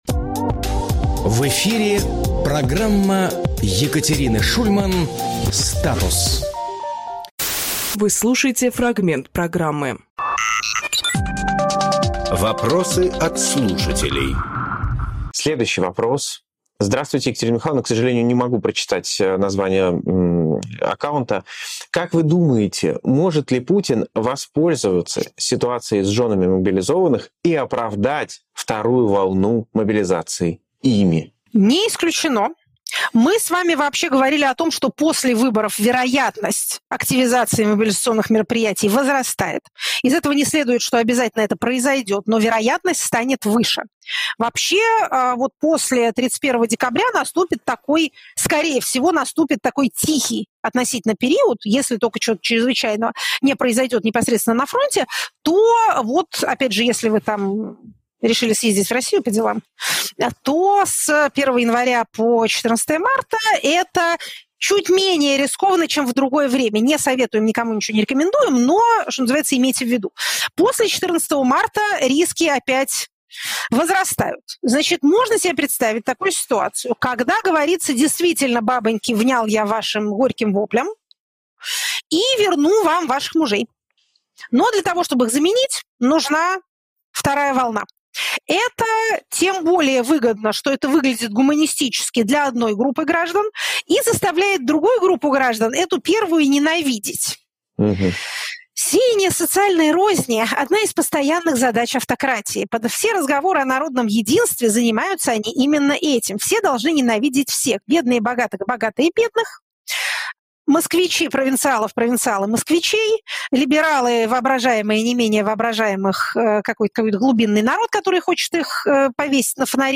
Екатерина Шульманполитолог
Фрагмент эфира от 12.12.23